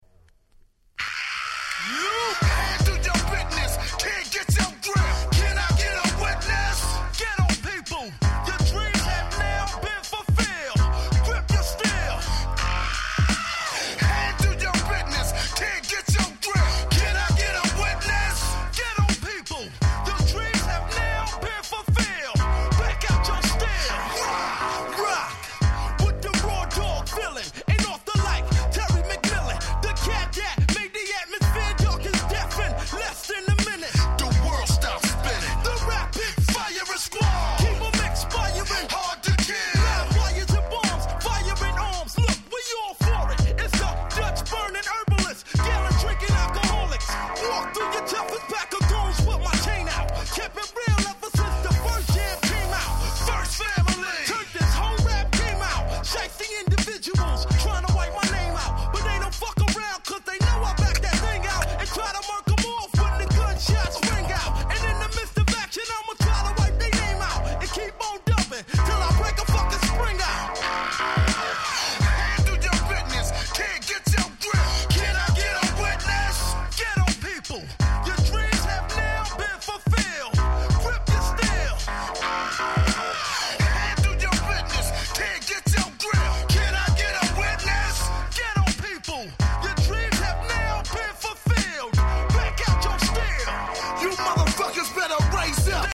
98' Smash Hit Hip Hop LP !!